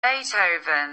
In phonetics his name is ethier beɪˌtəʊvən, or beɪtˌhəʊvən.
Play this audio to hear the correct pronunciation!